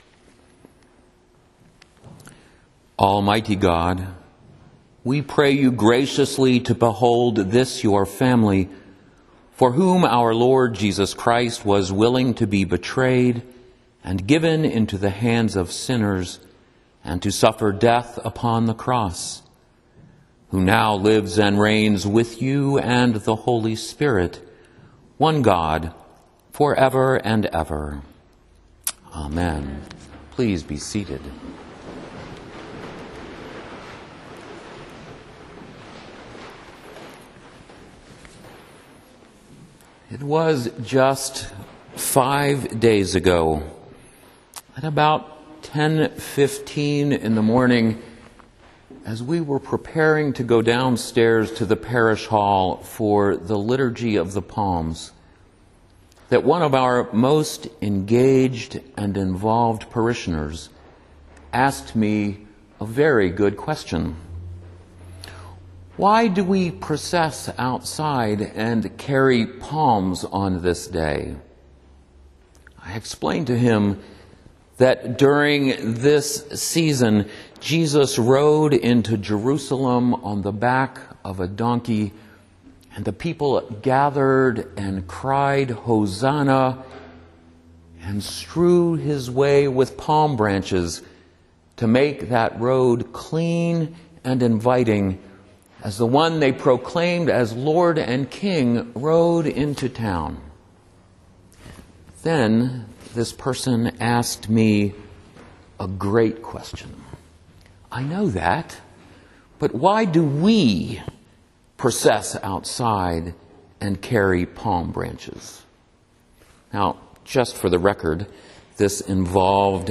sermon-good-friday-2014.mp3